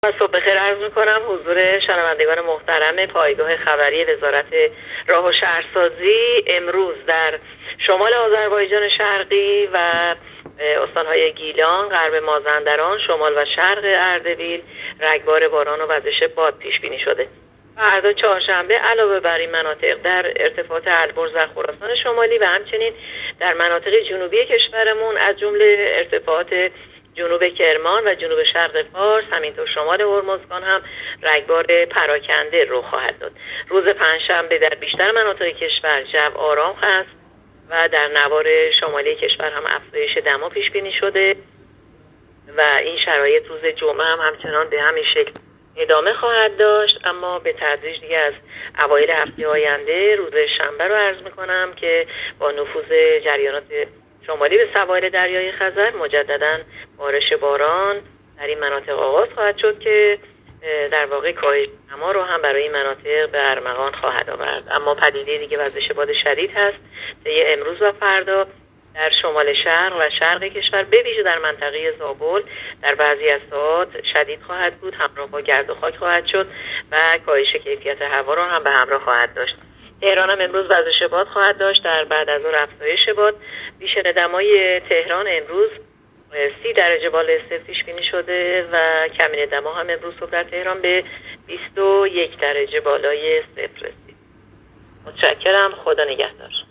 گزارش رادیو اینترنتی پایگاه‌ خبری از آخرین وضعیت آب‌وهوای ۲۵ شهریور؛